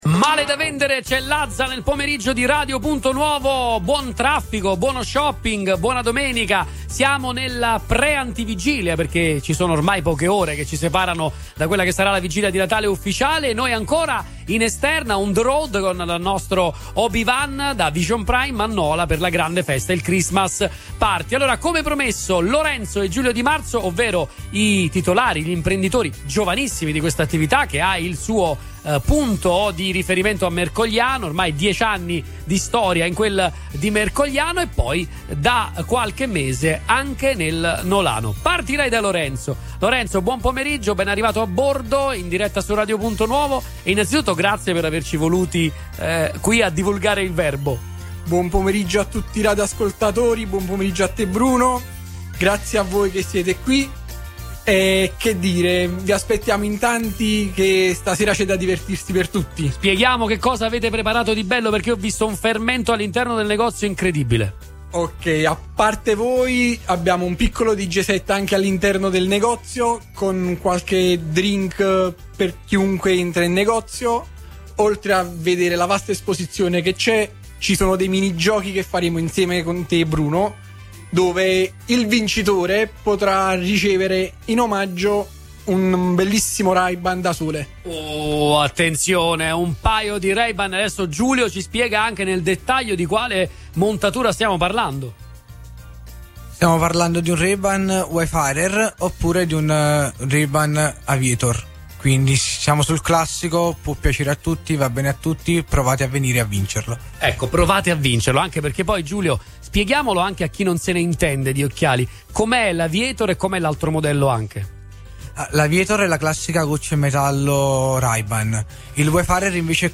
La regia mobile di Radio Punto Nuovo ha reso l’evento ancora più speciale, trasmettendo interviste in diretta con i protagonisti di Vision Prime e condividendo con gli ascoltatori lo spirito gioioso della giornata.